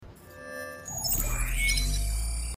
• Качество: высокое
Звук сканирования куклы из Игры в кальмара